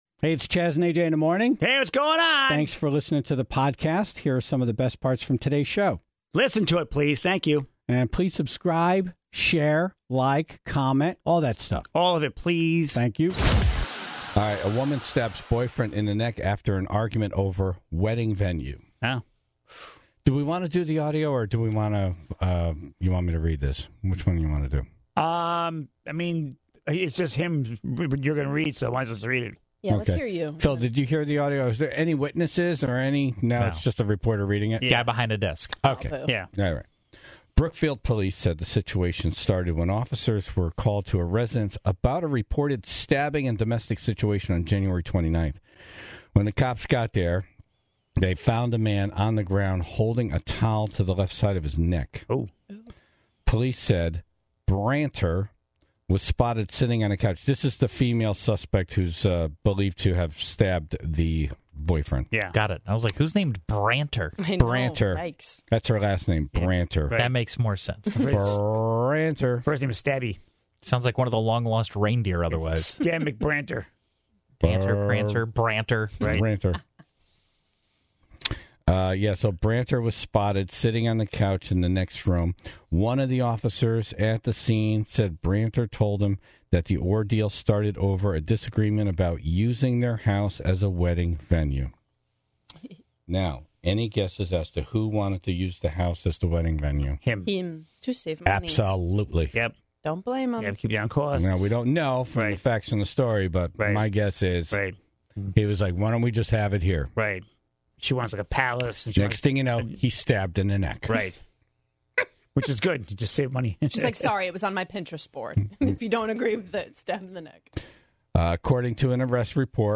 and took calls from two suitors from the Tribe.